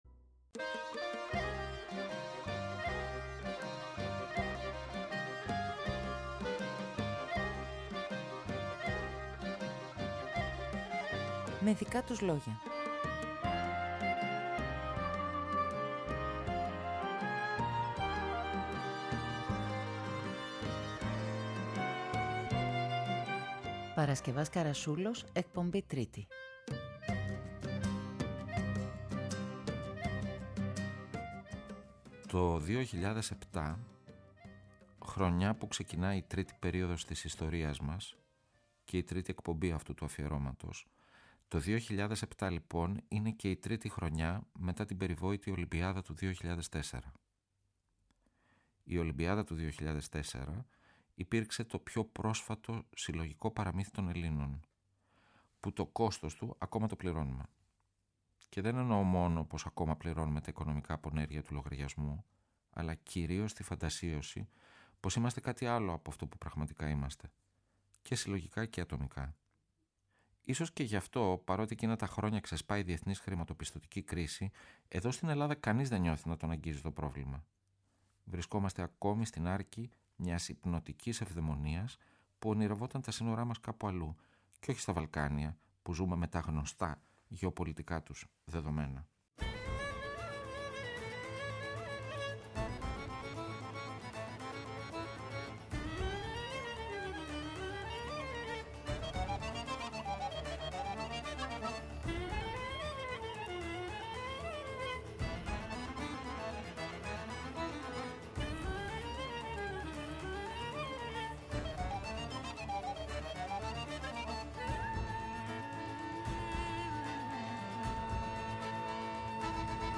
Στιγμιότυπα, ιστορίες, εικόνες, αναμνήσεις, σκέψεις. Πρόσωπα του πενταγράμμου αφηγούνται τη δική τους ιστορία…